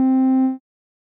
Techmino / media / sample / bass / 28.ogg